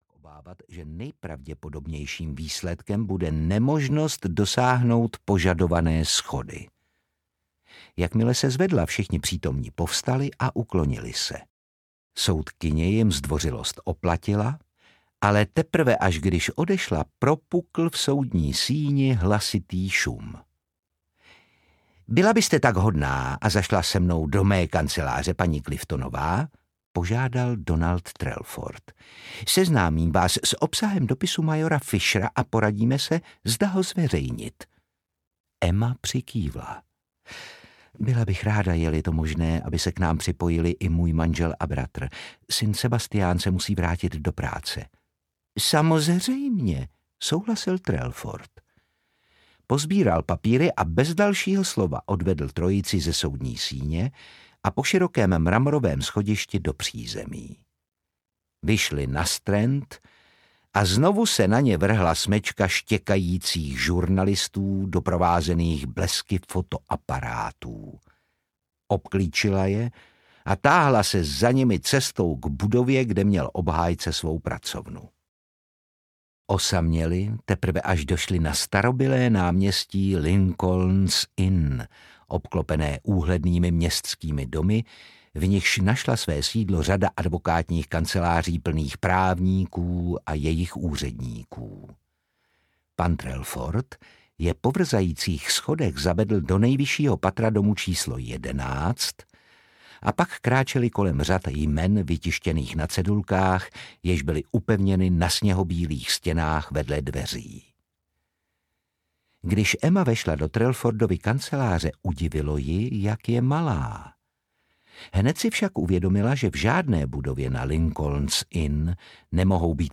A zrodí se hrdina audiokniha
Ukázka z knihy
• InterpretOtakar Brousek ml.